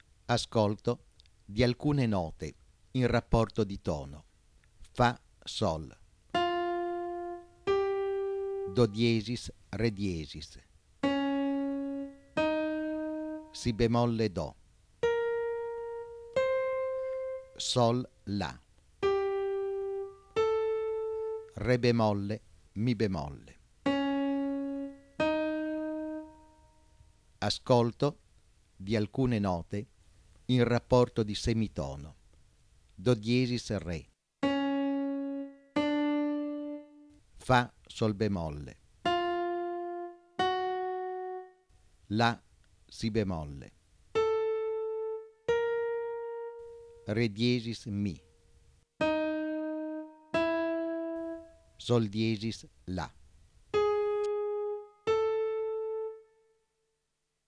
02.  Ascolto di alcuni esempi di tono e semitono con l’inserimento di note alterate.